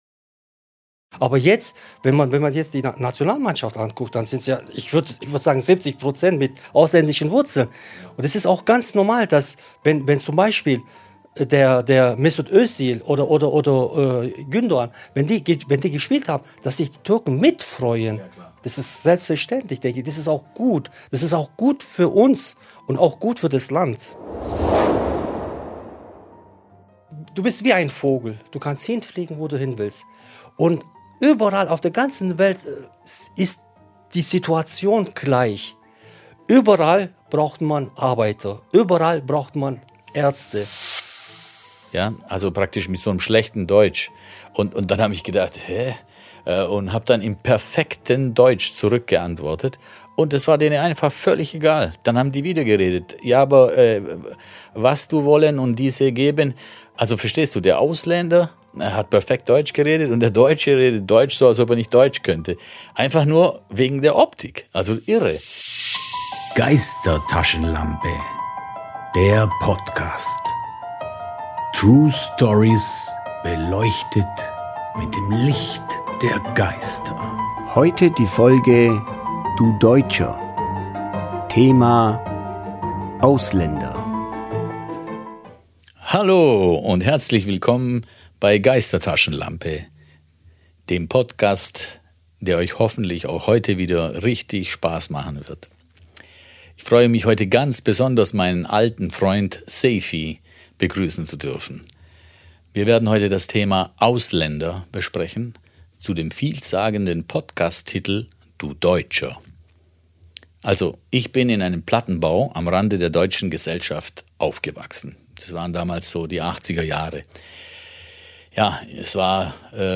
In dieser Folge geht es um Deutschland. Ausländer sprechen über ihr Leben in Deutschland und bieten überraschende Einblicke in ganz persönliche Erlebnisse.